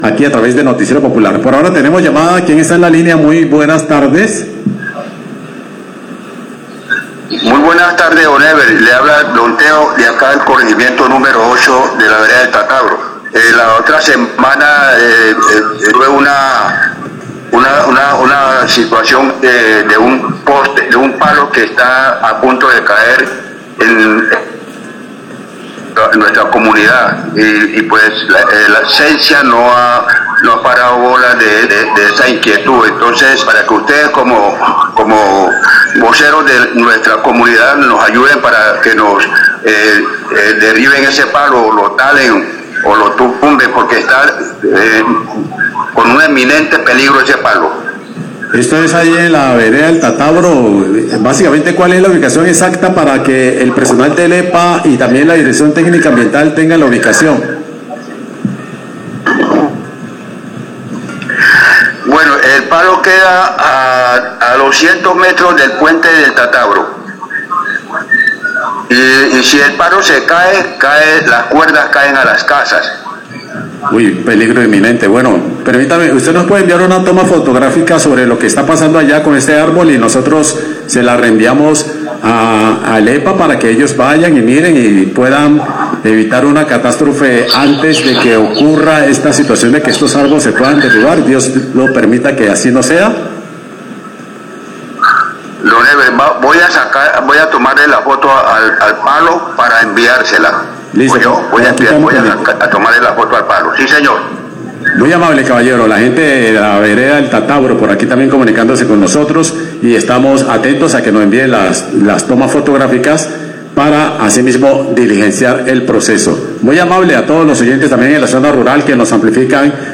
Radio
Oyente reporta arbol a punto de caerse sobre cuerdas de energía en vereda del Tatabro; manifiesta que la empresa de energía Celsia no ha hecho nada sobre este caso. El locutor informa que ya la información fue enviada para la oficina de gestion del riesgo y el EPA.